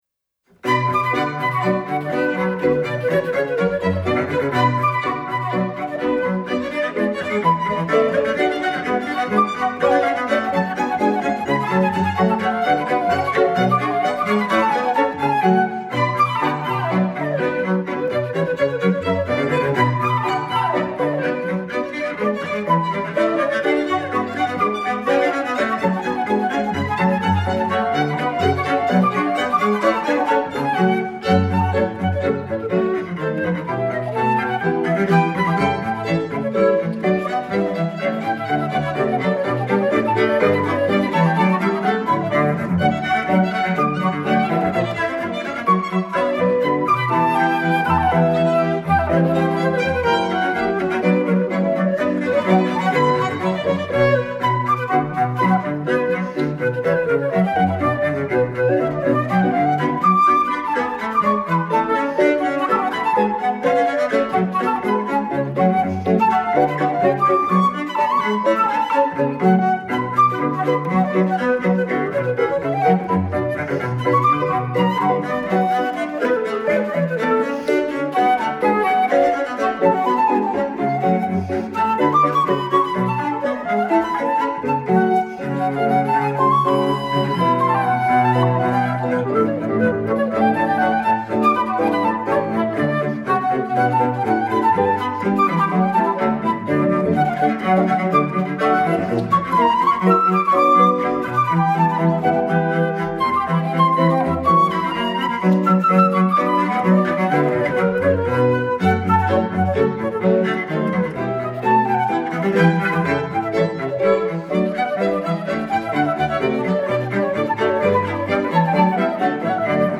Genre: Classical.